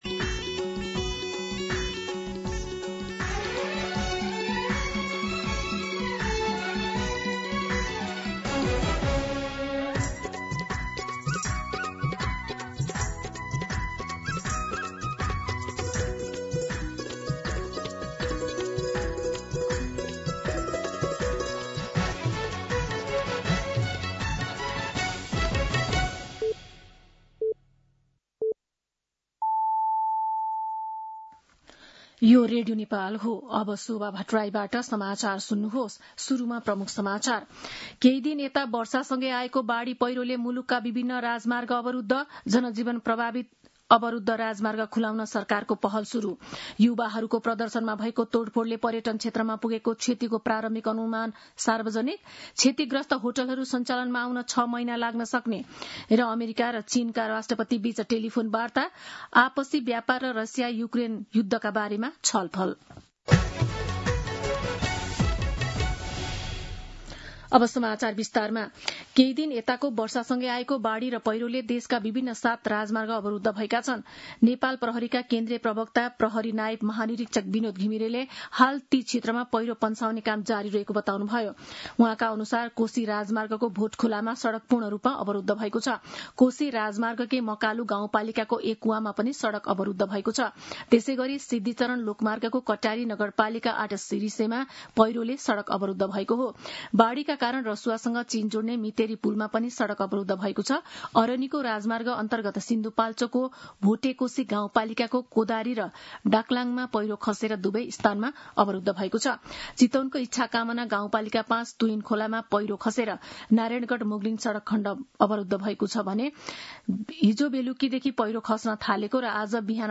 दिउँसो ३ बजेको नेपाली समाचार : ४ असोज , २०८२
3-pm-Nepali-News-3.mp3